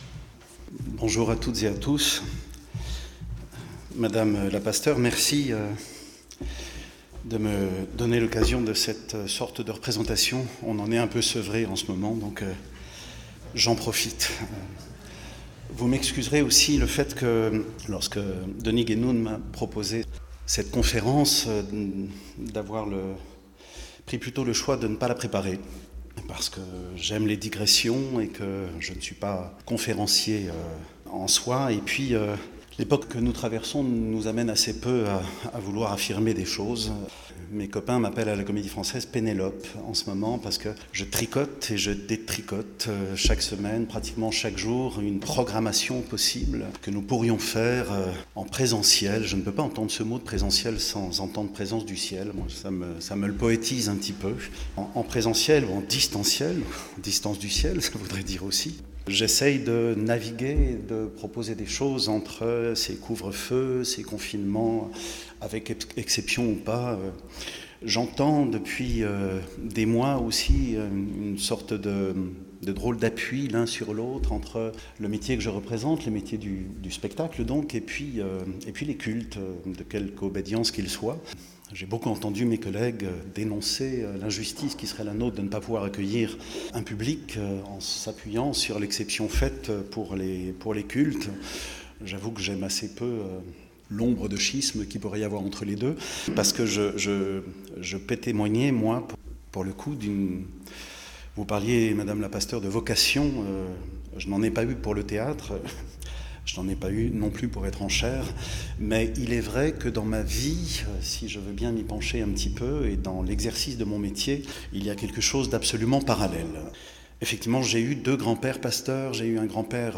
Culte-conférence : 2ème conférence - par Eric Ruf - Eglise Protestante Unie de la Bastille
Culte-conférence du 24 janvier 2021
Version courte (conférence sans culte) Les cultes-conférences 2021 "Comment allez-vous ?"